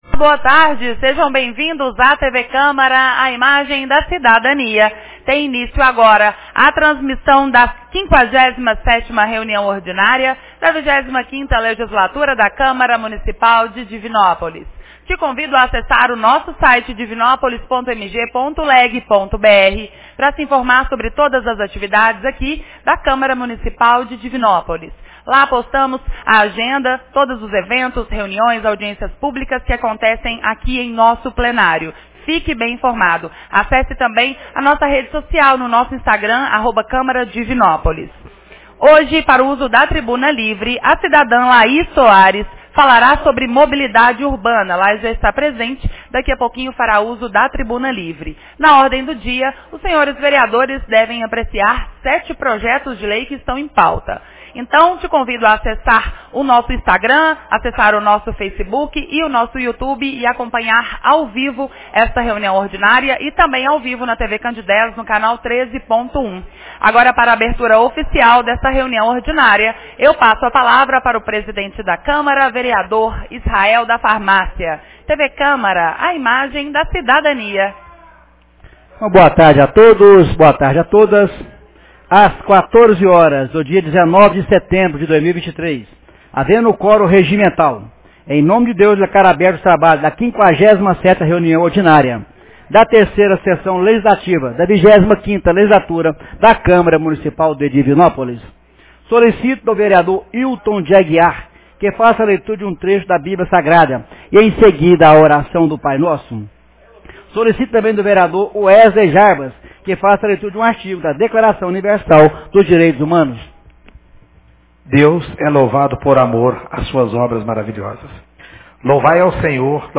57ª Reunião Ordinária 19 de setembro de 2023